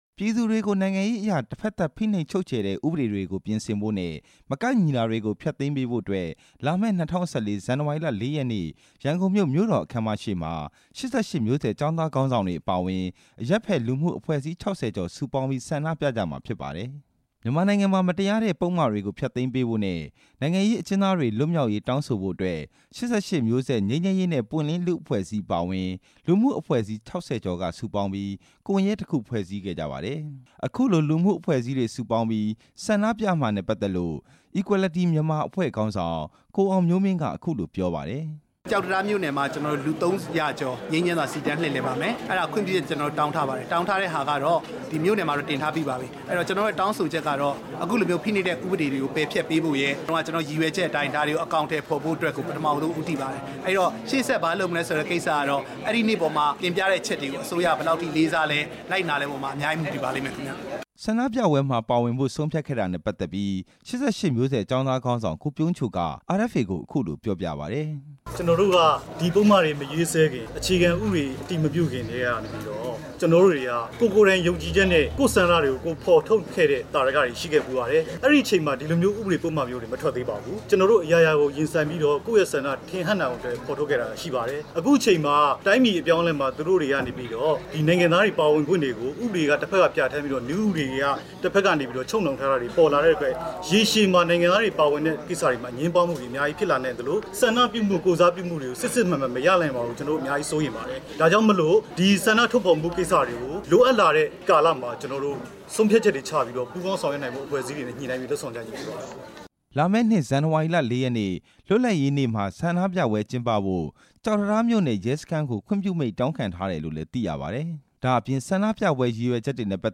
ဒီနေ့ကျင်းပတဲ့ သတင်းစာရှင်းလင်းပွဲအကြောင်း တင်ပြချက်